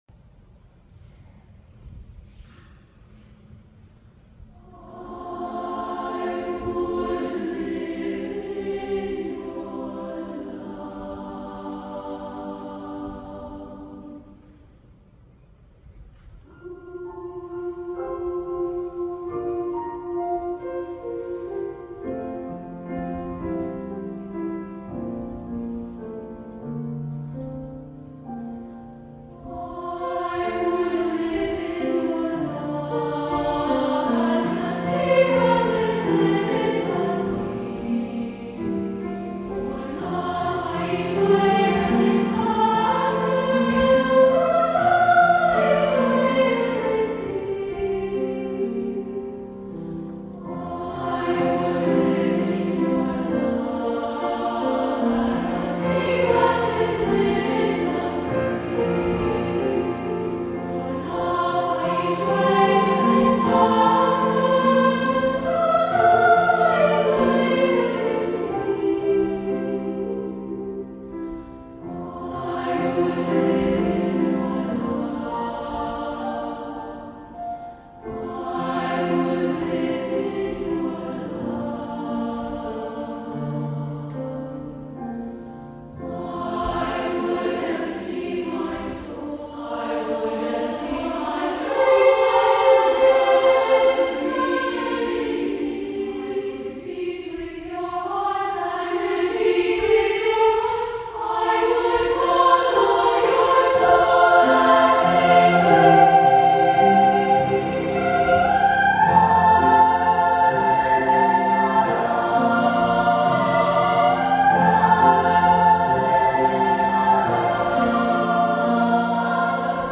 for SA div., piano